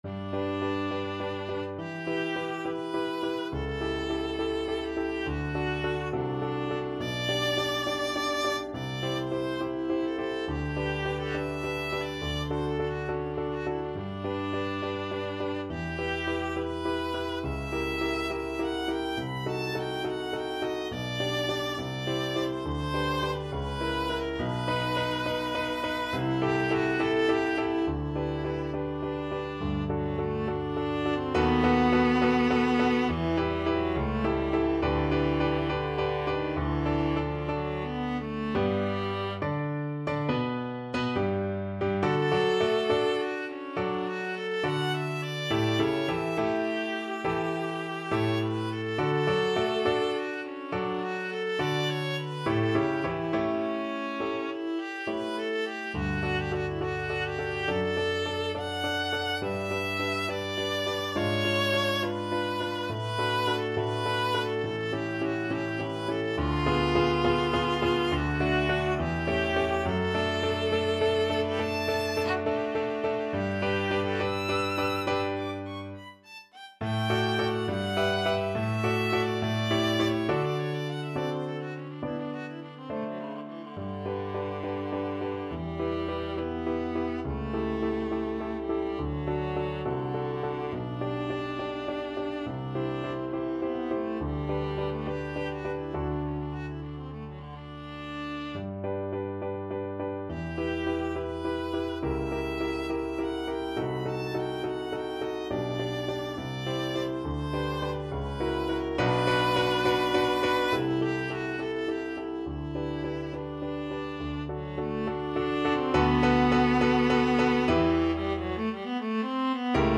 G major (Sounding Pitch) (View more G major Music for Viola )
4/4 (View more 4/4 Music)
Viola  (View more Advanced Viola Music)
Classical (View more Classical Viola Music)